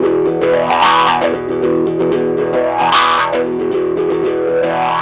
ROAD WAH.wav